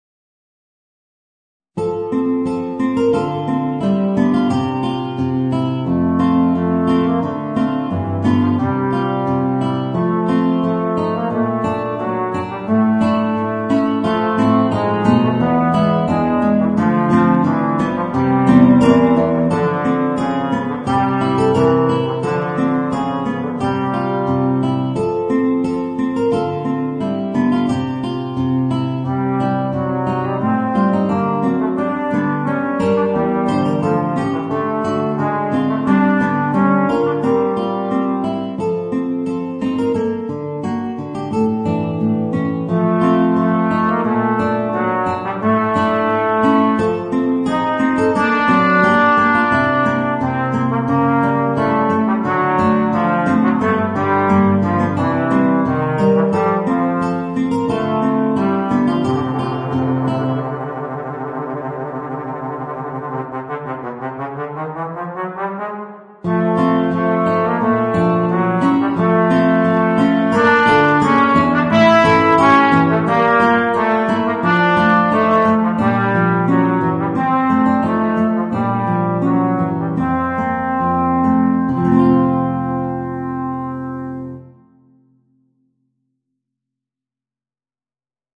Voicing: Guitar and Trombone